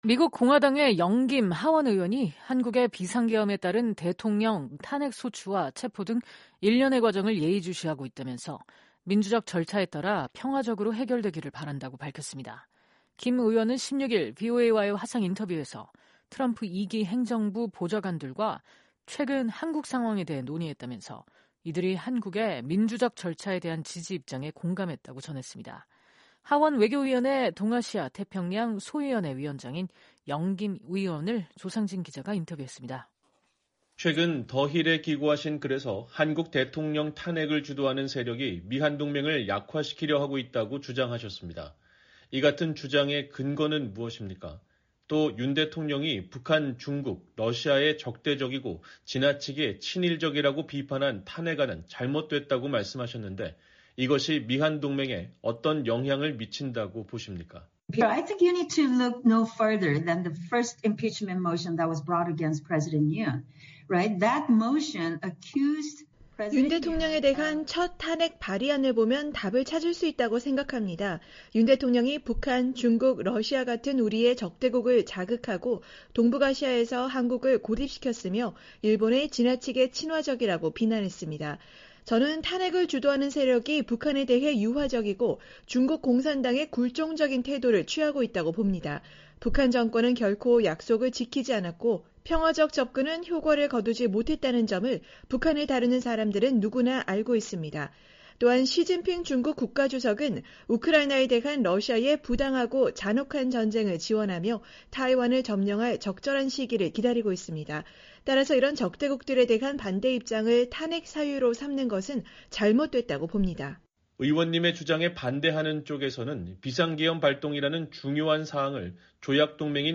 미국 공화당의 영 김 하원의원이 한국의 비상계엄에 따른 대통령 탄핵 소추와 체포 등 일련의 과정을 예의주시하고 있다면서 민주적 절차에 따라 평화적으로 해결되기를 바란다고 밝혔습니다. 김 의원은 16일 VOA와의 화상 인터뷰에서 트럼프 2기 행정부 보좌관들과 최근 한국 상황에 대해 논의했다면서, 이들이 한국의 민주절 절차에 대한 지지 입장에 공감했다고 전했습니다.